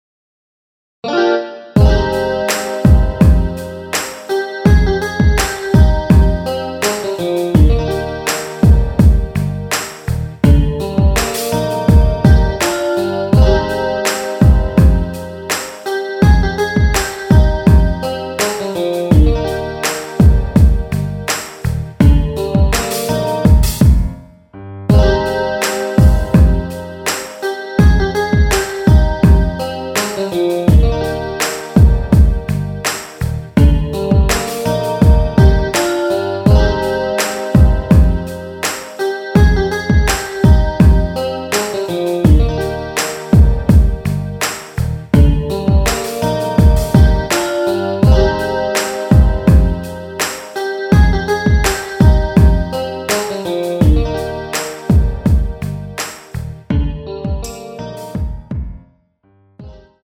원키에서(-1)내린 MR입니다.
엔딩이 페이드 아웃이라 노래 부르기 좋게 엔딩을 만들어 놓았습니다.
Eb
앞부분30초, 뒷부분30초씩 편집해서 올려 드리고 있습니다.